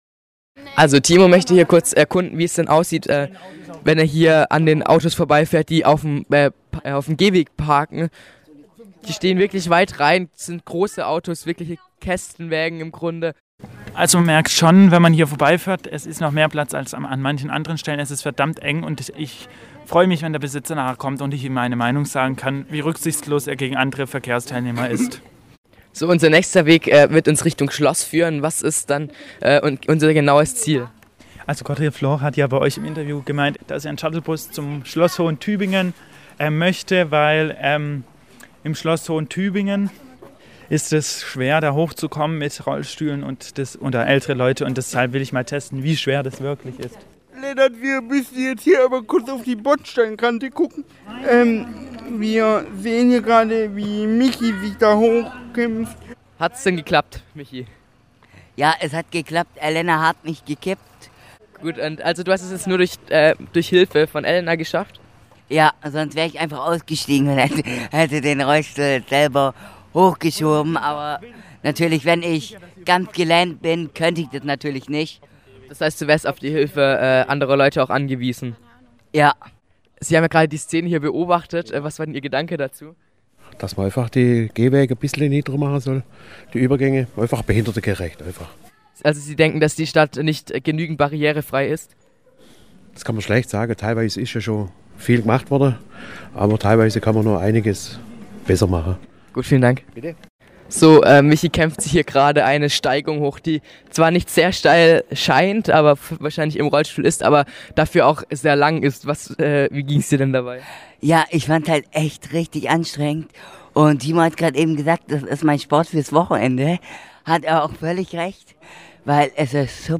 Mit dem Rollstuhl durch Tübingen - Eine Reportage
Die Jugendredaktion "All Inclusive" des Freien Radios Wüste Welle hat sich in den Sommerferien zwei Rollstühle ausgeliehen und ist damit durch die Tübinger Innenstadt gefahren.